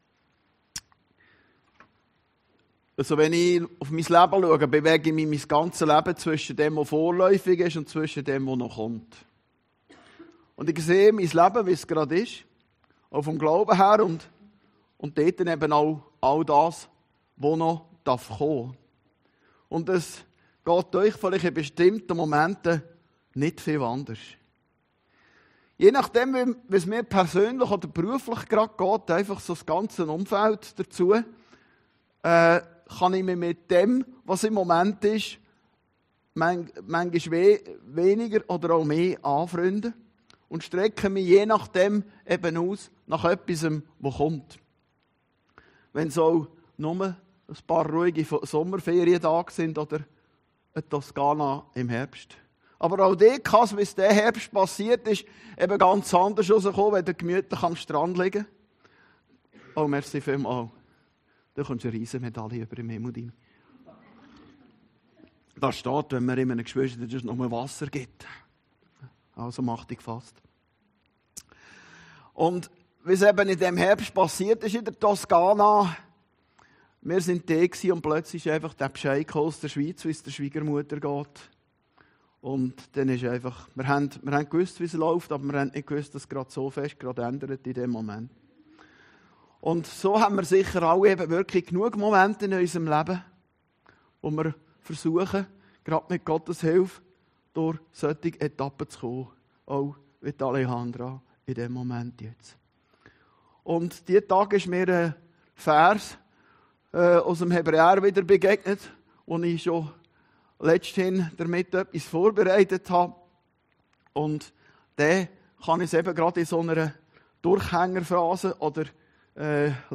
Predigten Heilsarmee Aargau Süd – Vorläufiges und Ewiges